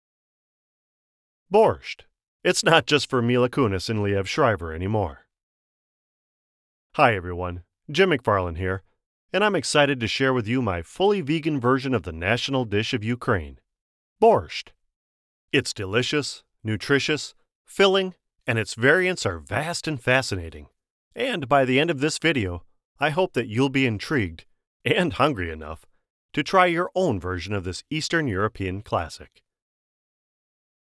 My voice is a rich baritone, a bit gravelly now that I'm in my mid-50s but still nice and strong, dignified but with lots of energy and expression, that is very well-suited to narrative deliveries for projects such as audiobooks, documentaries, explainers, and suitable broadcast-style advertisements.
Documentary
Words that describe my voice are baritone, gravelly, expressive.